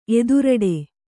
♪ eduraḍe